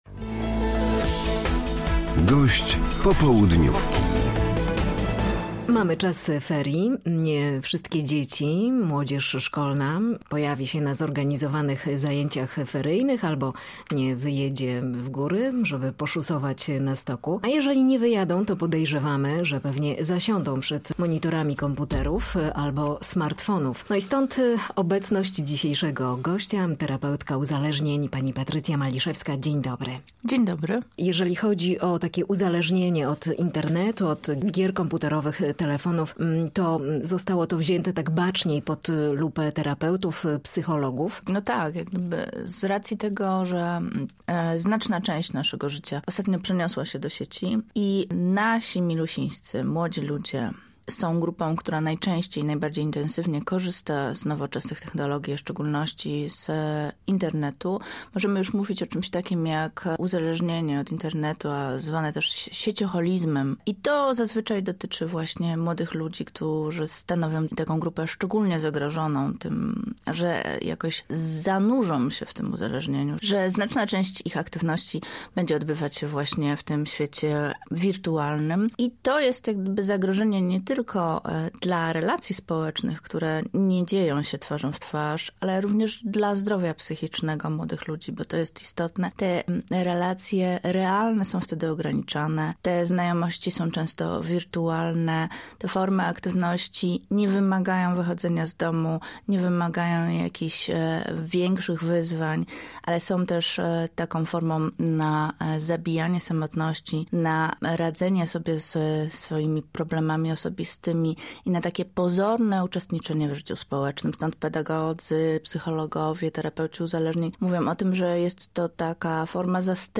Z terapeutką uzależnień rozmawia